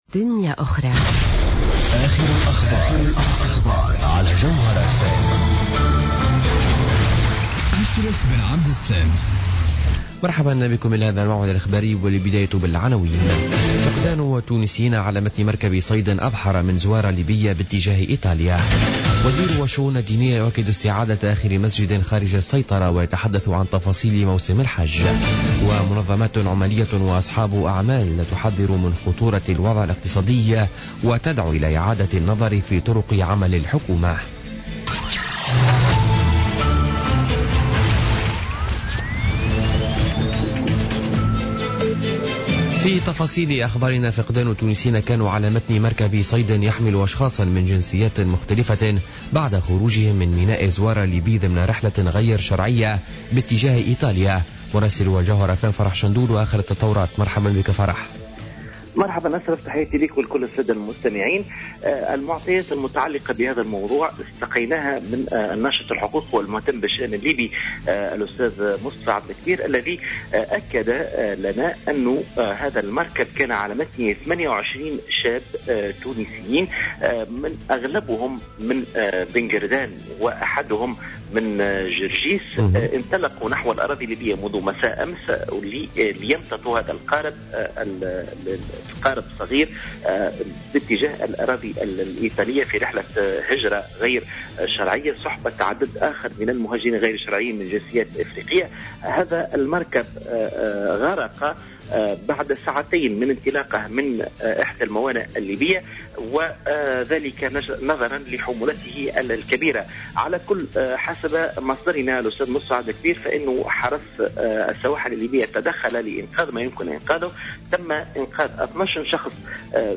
Journal Info 17h00 du Samedi 2 Juillet 2016